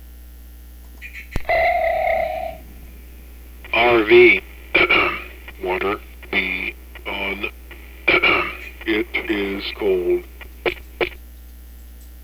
And here is one of several vocals that can come from this module.
BBALLBLU.GIF, 139B Ships Sonar Ping is the "Attention" alert.
BBALLBLU.GIF, 139B There is ample "clearing of the throats" to separate thoughts.
BBALLBLU.GIF, 139B The end of every sentence is punctuated with a vocal period. Actually, two here.